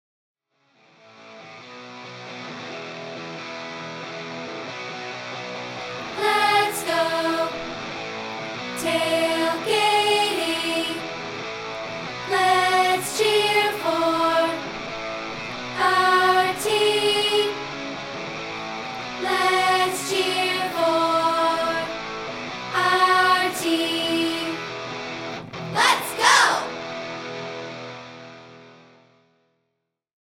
Here's a rehearsal track of part 2, isolated